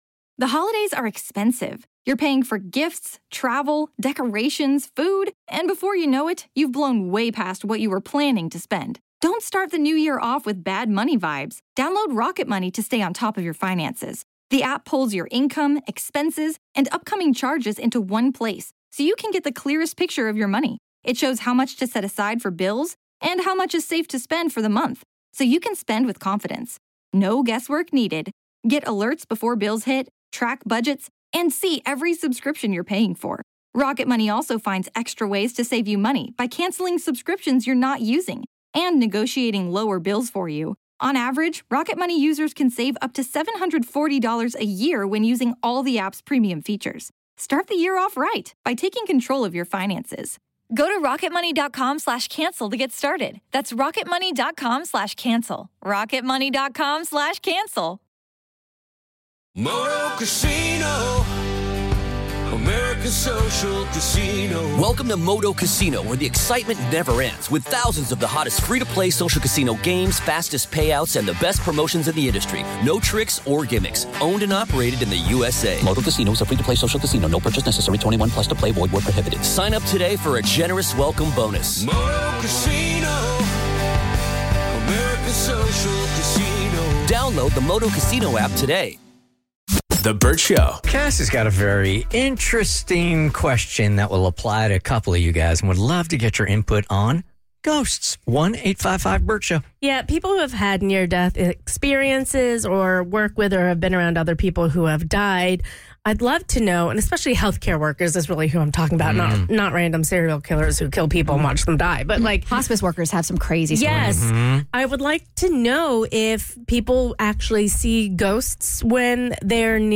Do ghosts start appearing when someone is dying? Listeners called in with their supernatural experiences that they thinks might be tied to their family members before they passed!